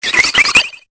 Cri de Chovsourir dans Pokémon Épée et Bouclier.